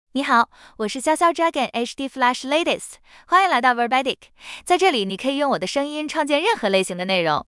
Xiaoxiao Dragon HDFlash LatestFemale Chinese AI voice
Xiaoxiao Dragon HDFlash Latest is a female AI voice for Chinese (Mandarin, Simplified).
Voice sample
Listen to Xiaoxiao Dragon HDFlash Latest's female Chinese voice.
Female